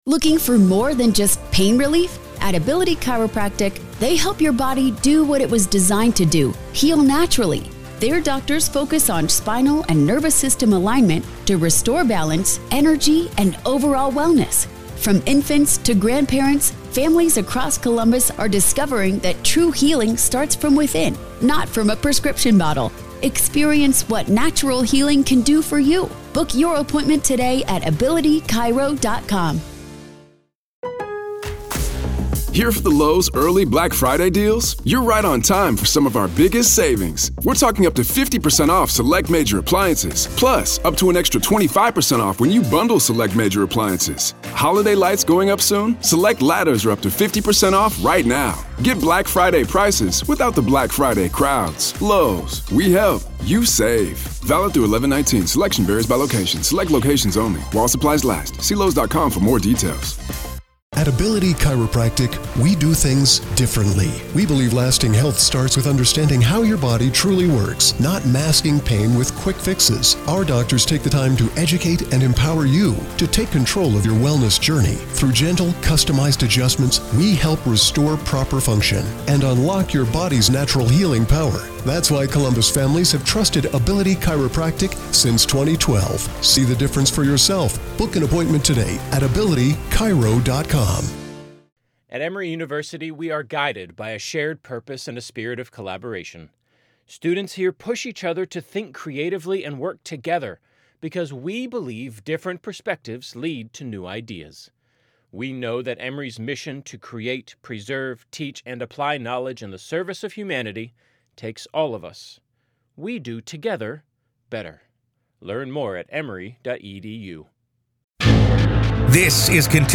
Welcome to a special episode of "The Trial of Karen Read," where today, we find ourselves inside the courtroom for a hearing in the case against Karen Read.